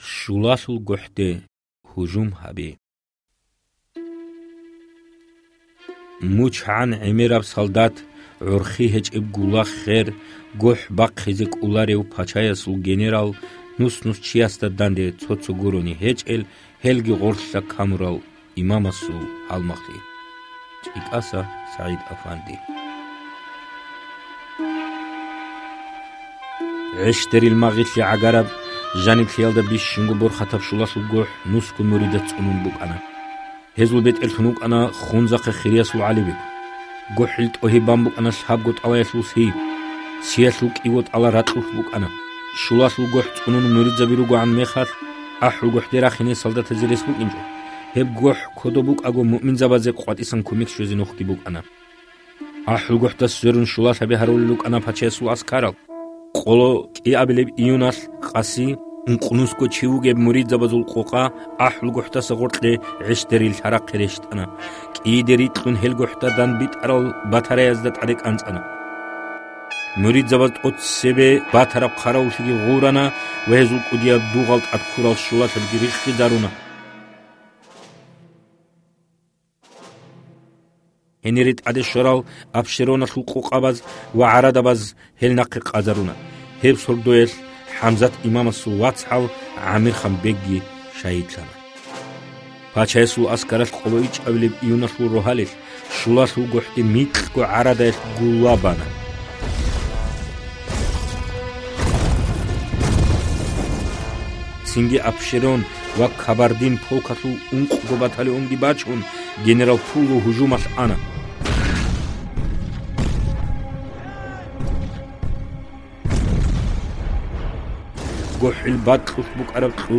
ТIехьалъул аудио версия. 20-29 бутIаби.